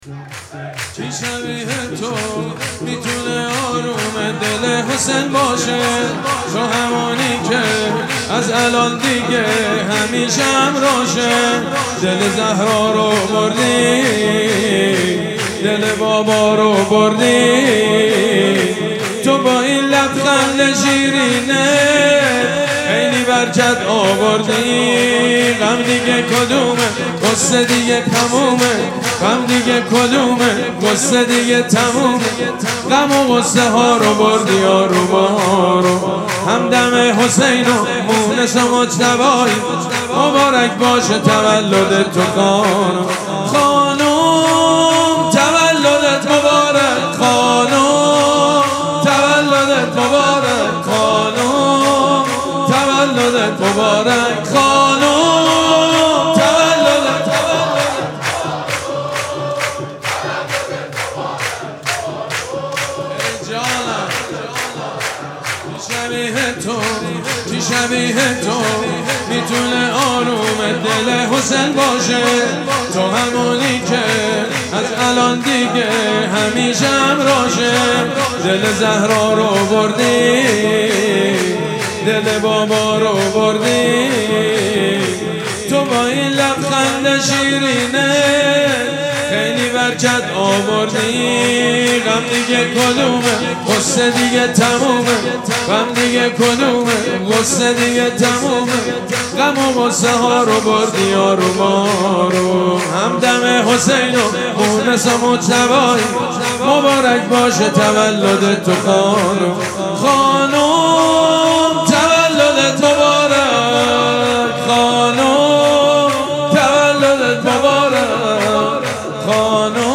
مراسم جشن ولادت حضرت زینب سلام‌الله‌علیها
حسینیه ریحانه الحسین سلام الله علیها
شور
حاج سید مجید بنی فاطمه